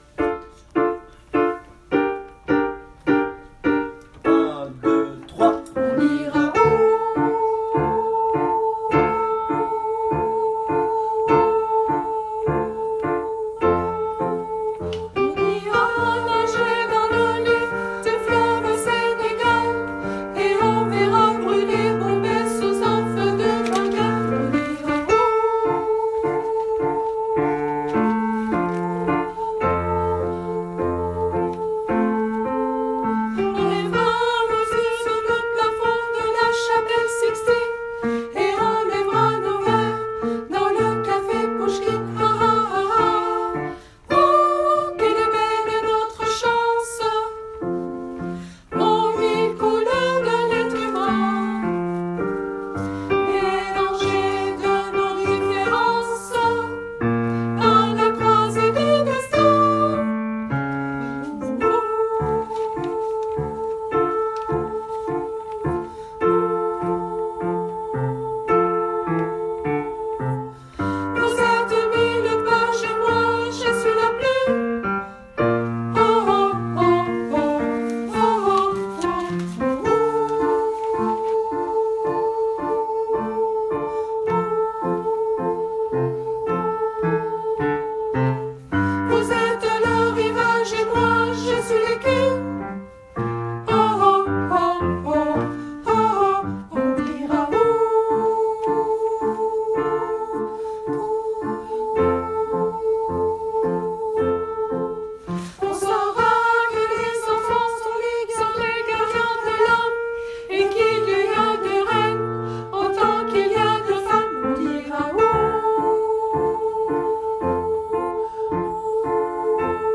On-ira-sopranis-1.mp3